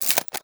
CAMERA_Shutter_02_mono.wav